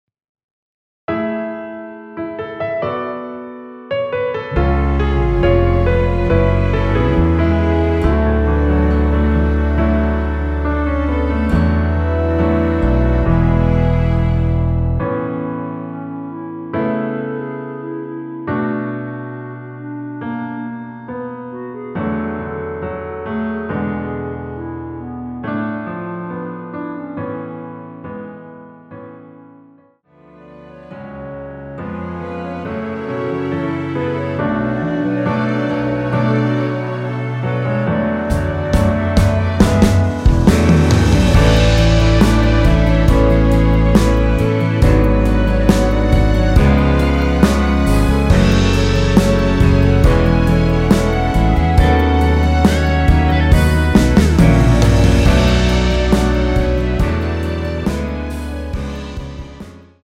원키에서(-3)내린 (1절+후렴)으로 진행되는 멜로디 포함된 MR입니다.(본문의 가사와 미리듣기 확인)
앞부분30초, 뒷부분30초씩 편집해서 올려 드리고 있습니다.